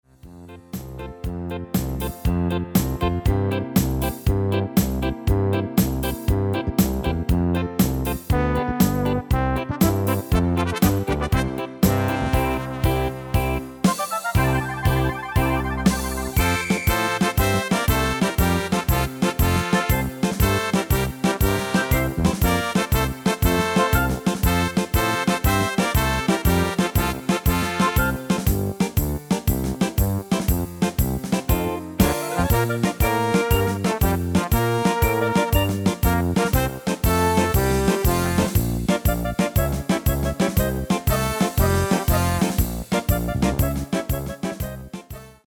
Medley Extended MIDI File Euro 14.50
Demo's zijn eigen opnames van onze digitale arrangementen.